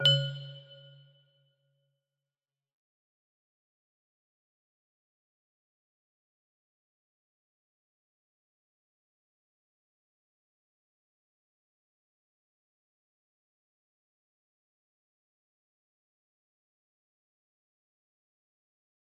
Test music box melody
Full range 60